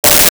Sci Fi Beep 14
Sci Fi Beep 14.wav